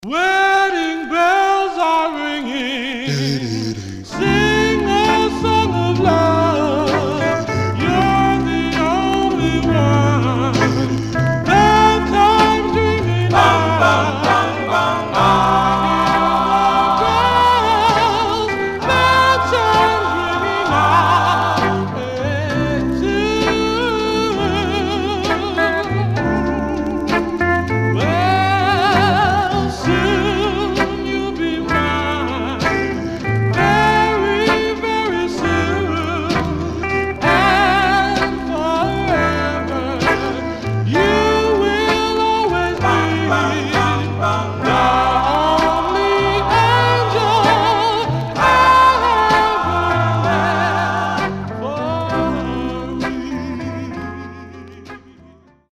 Condition Some surface noise/wear Stereo/mono Mono
Male Black Groups